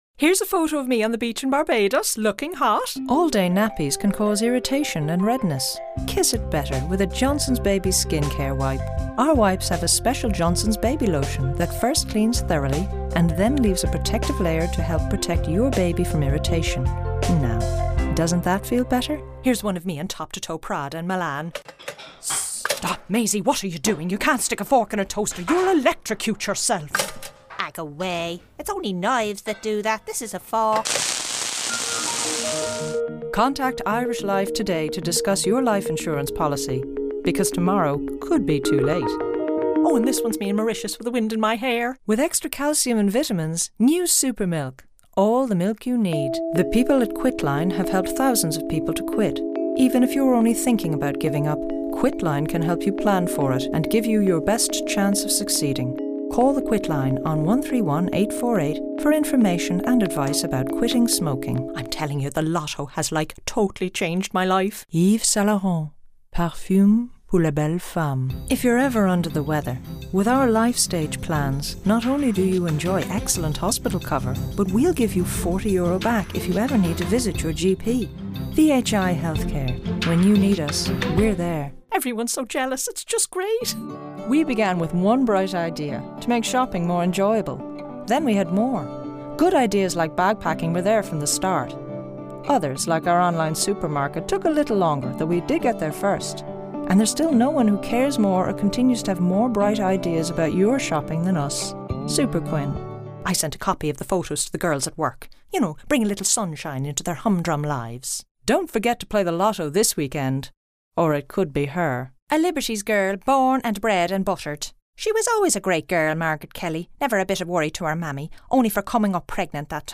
My voice is low to mid-range in timbre. My voice is warm, trustworthy and professional.
Sprecherin englisch. Warm; friendly; corporate; characters; comedy; trustworthy; professional; straight read.
female voice over talent english. Warm; friendly; corporate; characters; comedy; trustworthy; professional; straight read.